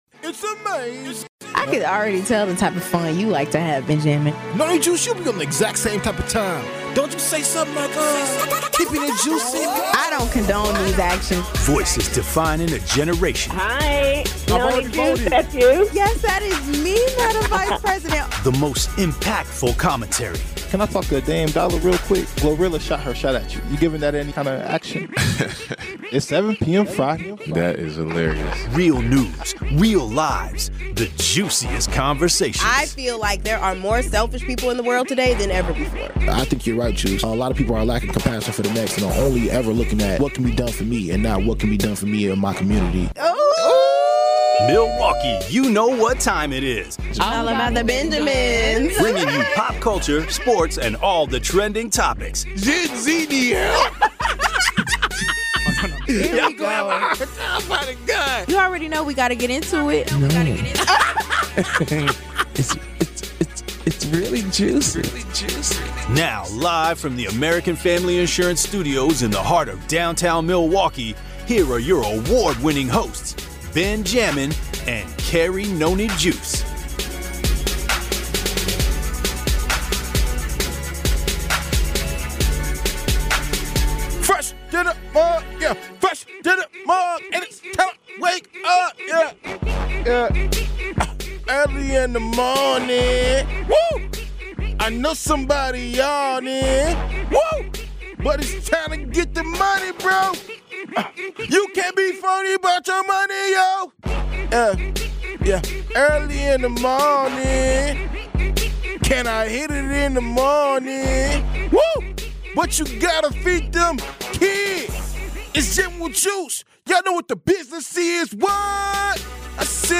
We discuss their impact, their artistry, and the void left behind after their passing. Then, we dive into a thought-provoking question: Should celebrities be seen as role models? Tune in as we unpack this question with honest insights, engaging conversation, and, of course, a little bit of that musical flavor we love.